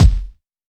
Dilla Kick 04.wav